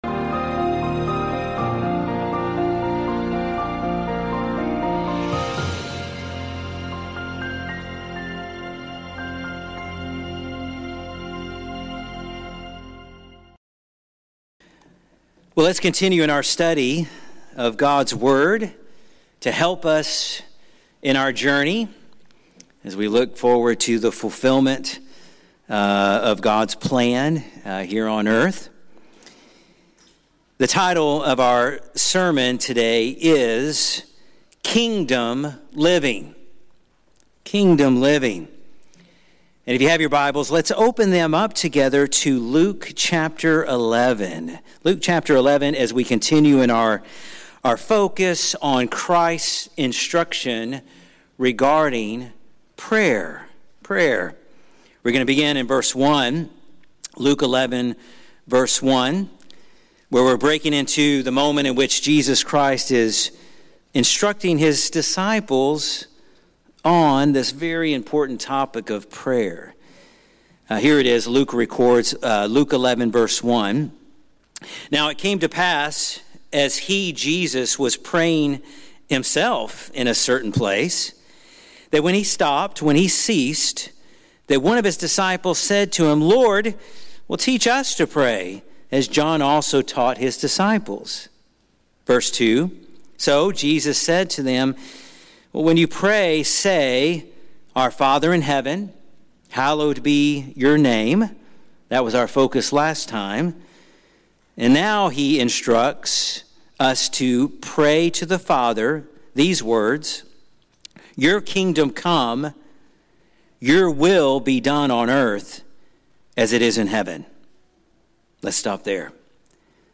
This sermon calls us to see that all of life is lived between two kingdoms, the City of Man, built for self, and the City of God, established by God and ruled by King Jesus. It is through Jesus we are graciously transferred from one to the other.